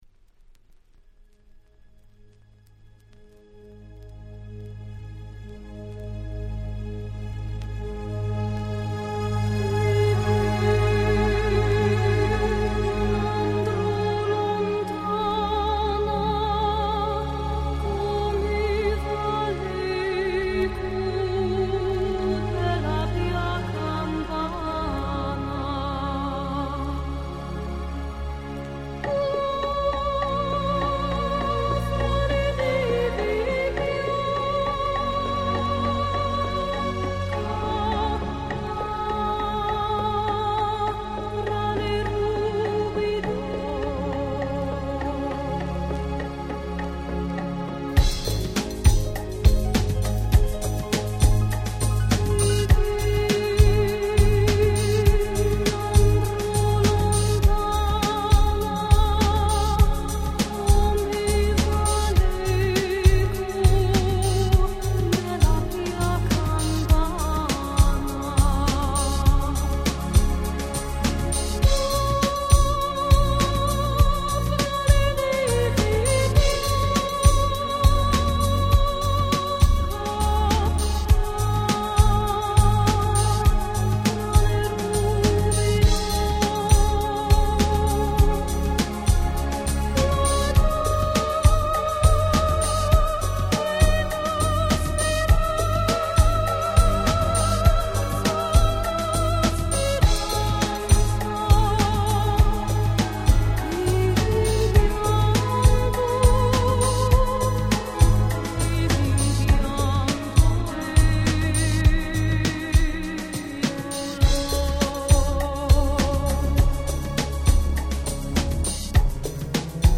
【Condition】B (薄いスリキズ、プレスによるプチノイズ箇所あり。)
90' Nice Ground Beat !!
美しく壮大なアレンジでもうため息しか出ません。